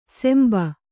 ♪ semba